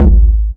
GS Phat Kicks 006.wav